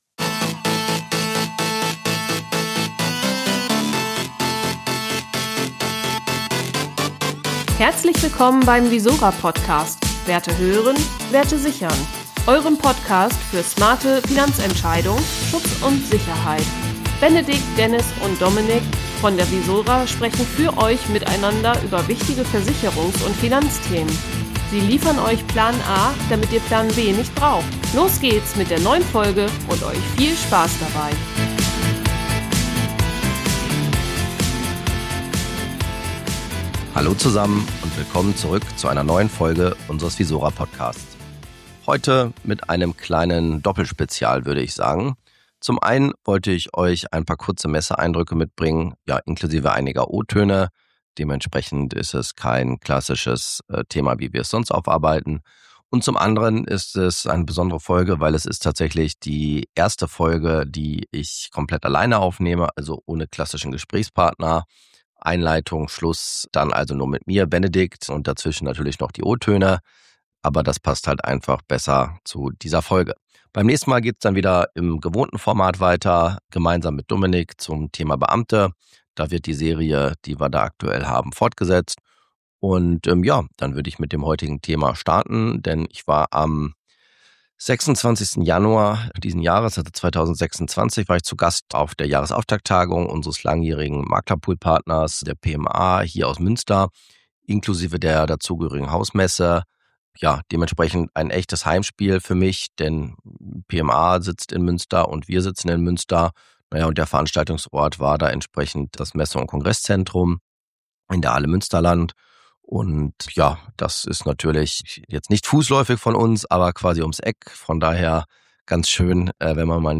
Messeeindrücke 2026: Stimmen & Einblicke von der pma: Jahresauftakttagung ~ visora Versicherungsmakler – Werte hören. Werte sichern. Podcast